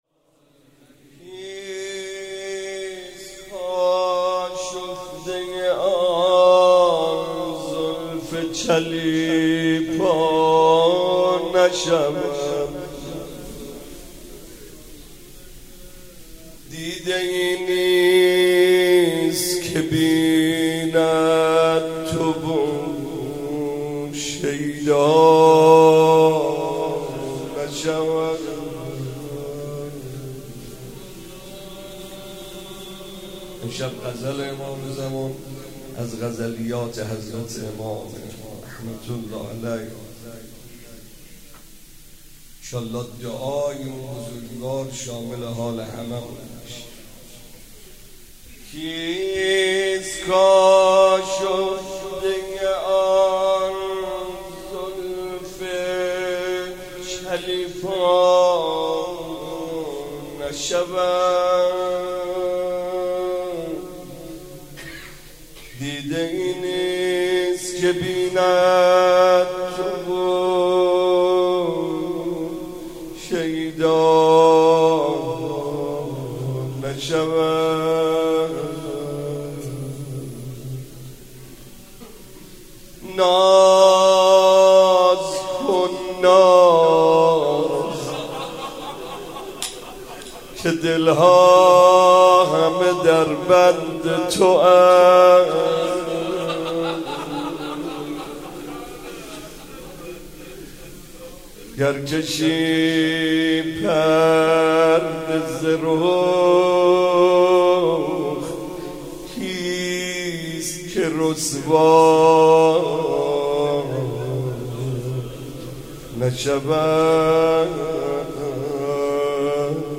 شب 20 رمضان97 - مناجات - کیست آشفته آن زلف چلیپا نشود
ماه رمضان 97